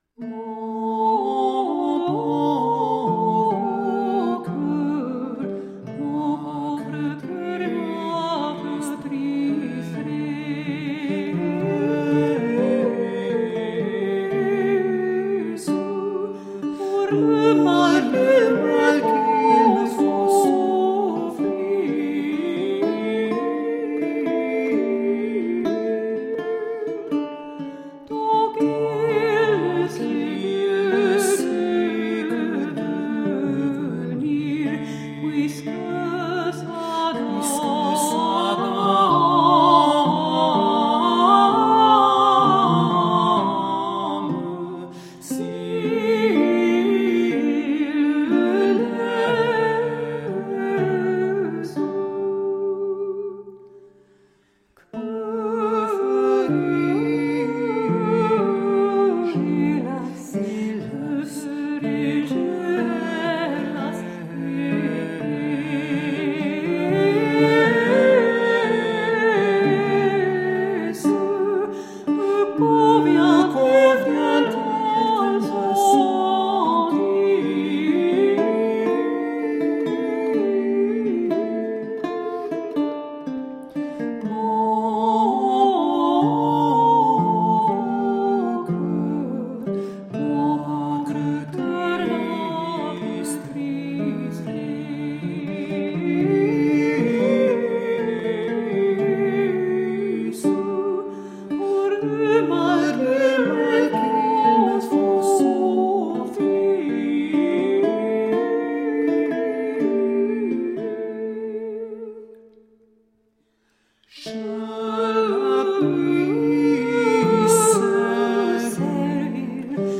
Late-medieval vocal and instrumental music